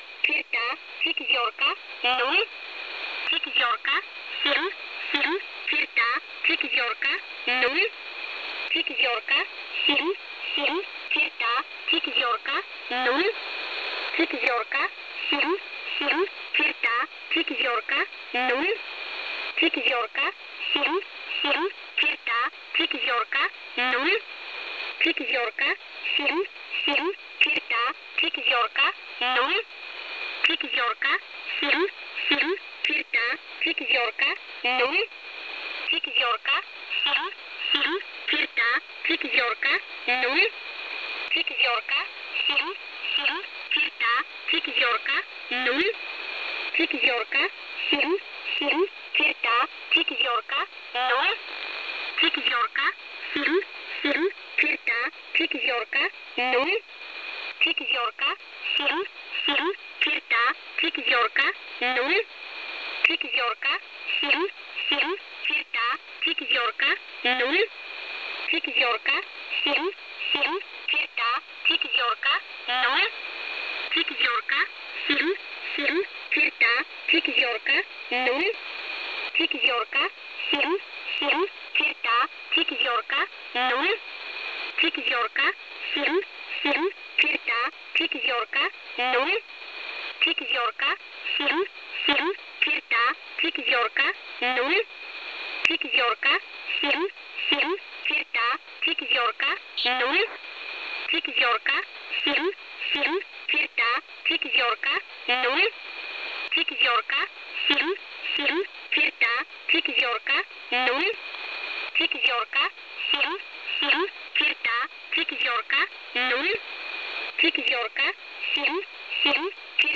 found some numbers station of sorts on shortwave a couple days back. could be a legitimate one, a pirate one, a parody one, unsure. can't quite identify what language this is but some of the numbers share the same pronunciation as many slavic languages (1 attachments)
can't quite identify what language this is but some of the numbers share the same pronunciation as many slavic languages